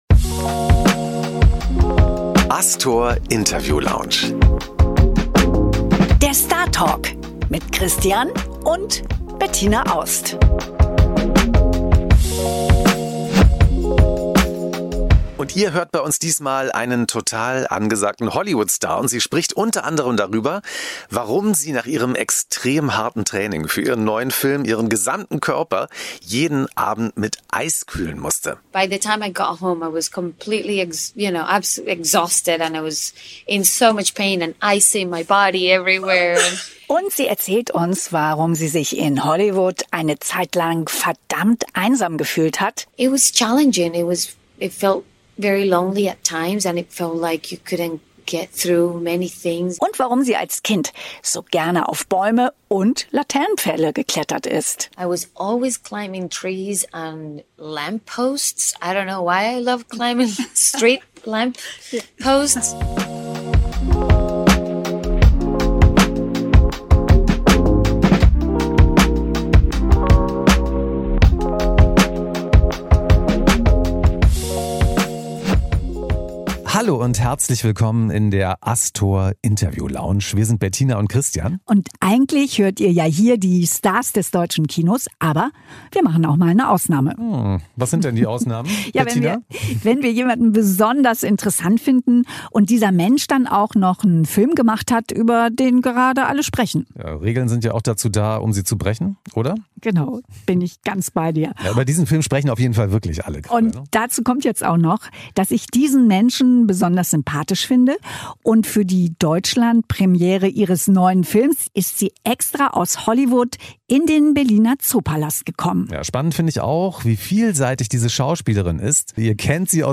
In unserer neuen Episode der ASTOR INTERVIEW LOUNGE spricht sie über ihr krasses Trainingsprogramm, blaue Flecken, Keanu Reeves, Einsamkeit in Hollywood und ihren Jugendschwarm.